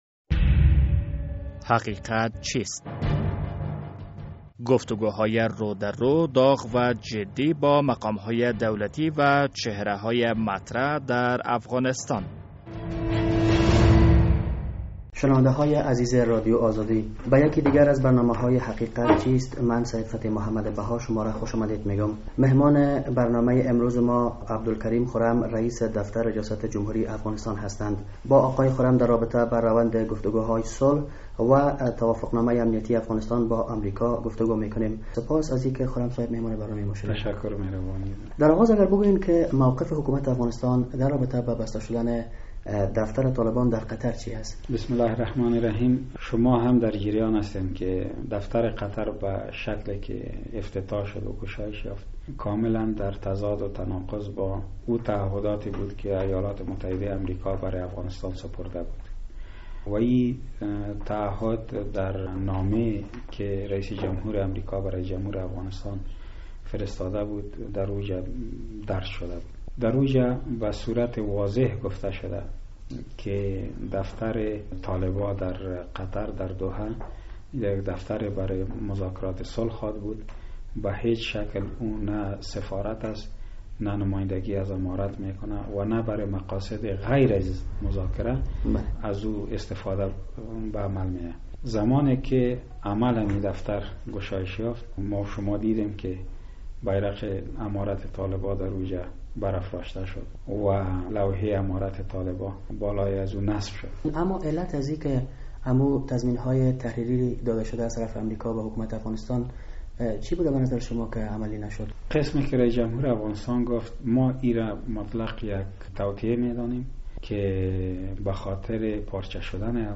در این برنامهء حقیقت چیست عبدالکریم خرم رییس دفتر ریاست جمهوری افغانستان مهمان است. با آقای خرم در رابطه به تلاش های صلح و توافقنامهء امنیتی میان کابل و واشنگتن گفتگو کرده ایم....